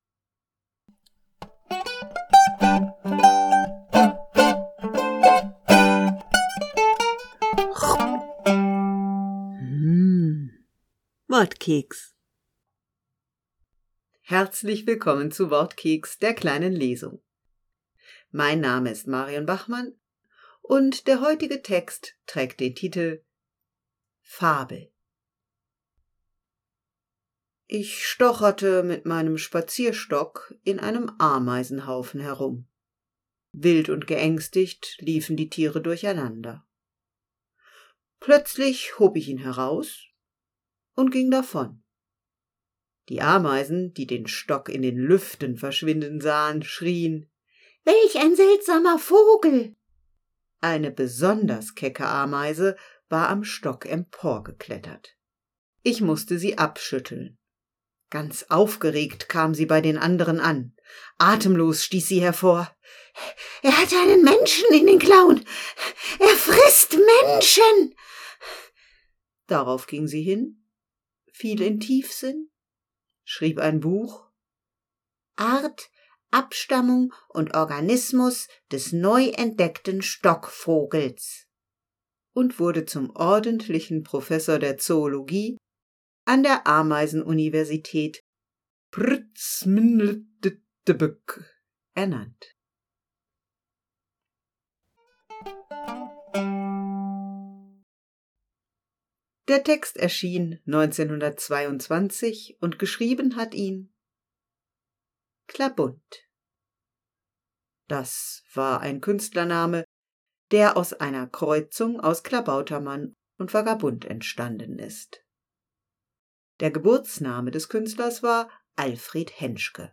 Wortkeks - die kleine Lesung
Die Lesung mit Quizeckchen heute mit einem Text von jemandem, der